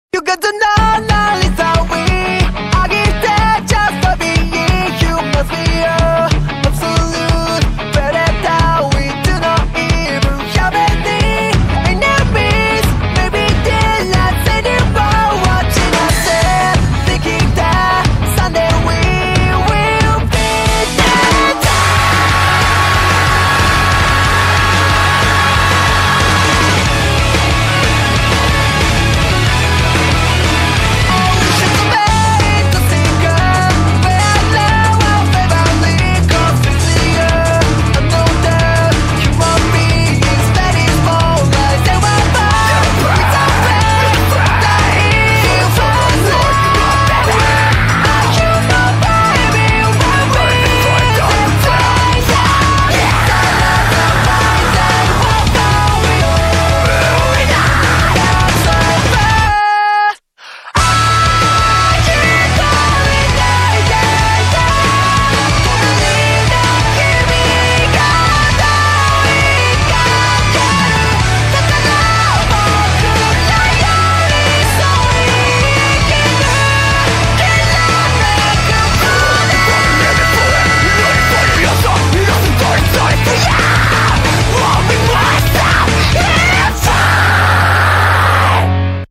BPM92-184